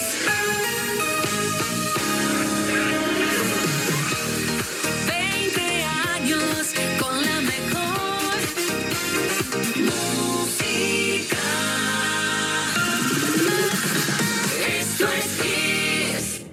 Indicatiu dels 20 anys de l'emissora